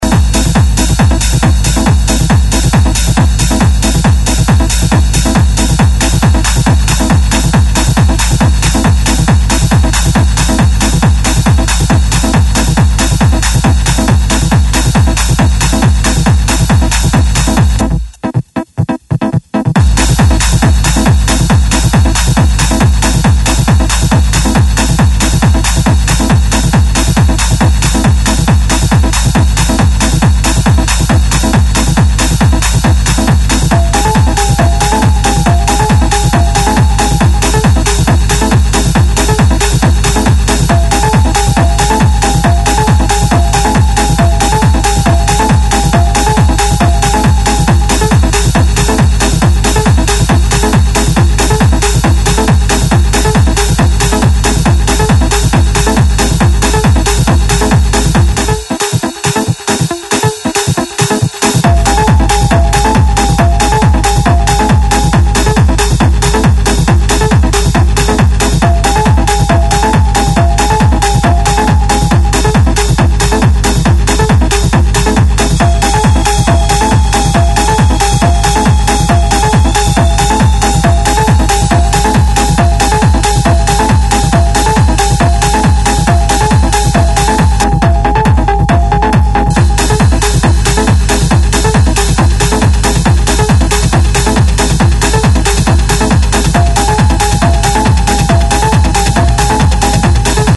El ultraclásico estacional de Detroit
Todas las pistas están remasterizadas para la serie.